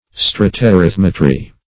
Search Result for " stratarithmetry" : The Collaborative International Dictionary of English v.0.48: Stratarithmetry \Strat`a*rith"me*try\, n. [Gr.